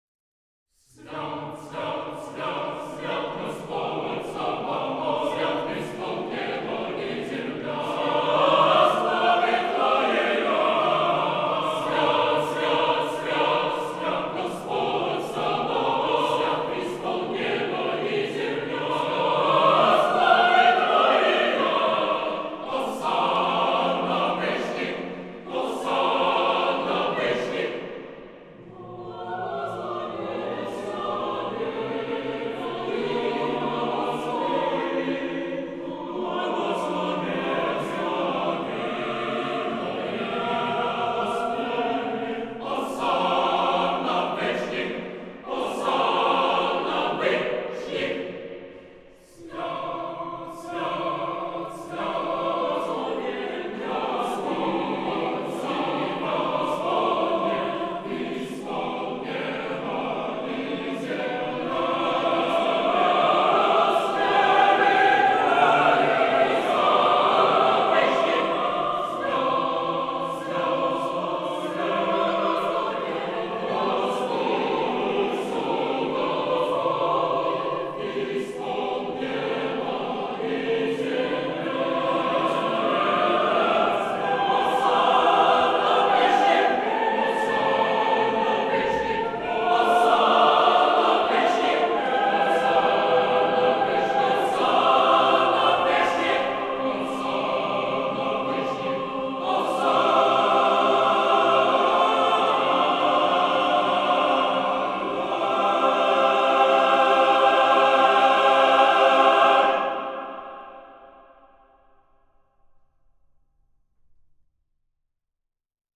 Воскресное литургическое пение 2.66 MB